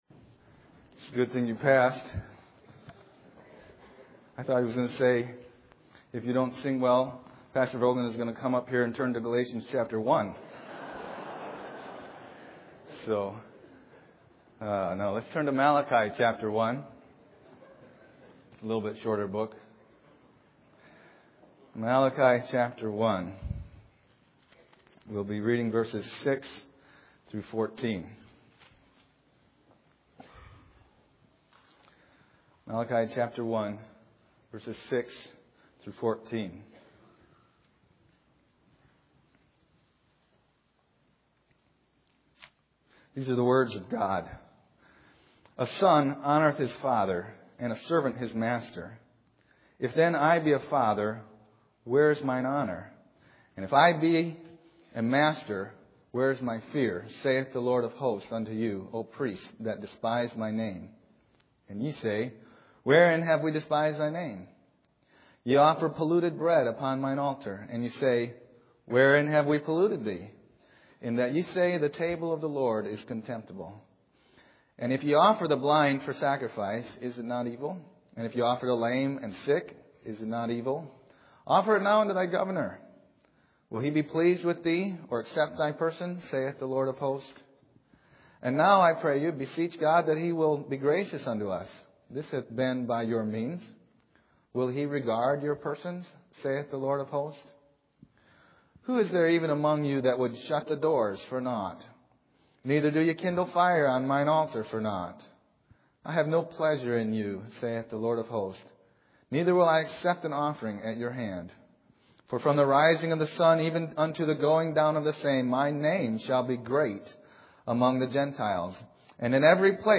Bible Text: Malachi 1:6-14 | Preacher